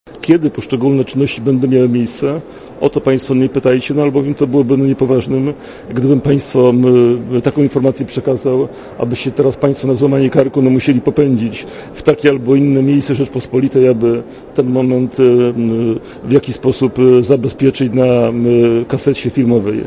* Mówi prokurator Olejnik*
Kiedy to nastąpi, o to mnie państwo nie pytajcie, tego powiedzieć nie mogę - powiedział Olejnik w Katowicach dziennikarzom.